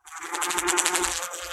buzz2.wav